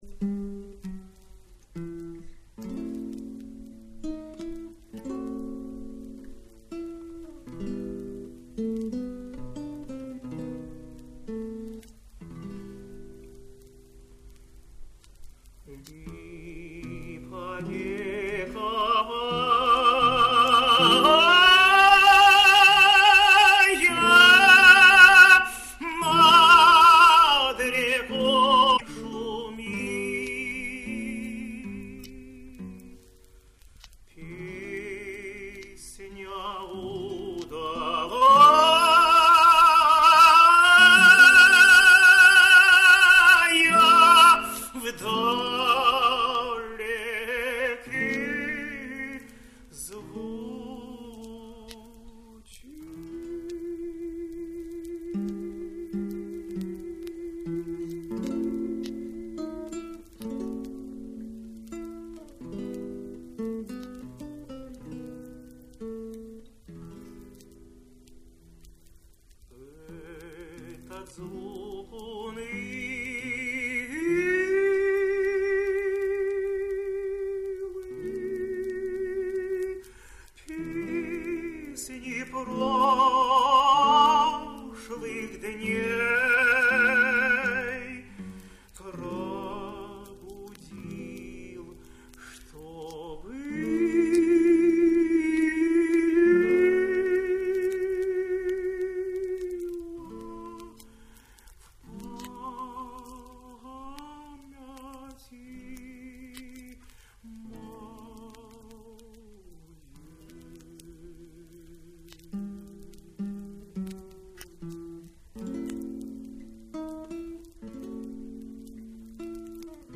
chitarra sola
chitarra